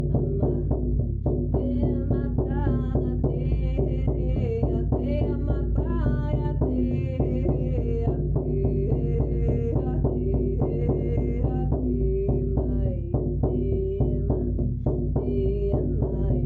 Plongez dans une expérience transformative avec ce voyage chamanique guidé de 38 minutes, spécialement conçu pour vous initier à la rencontre avec vos guides spirituels.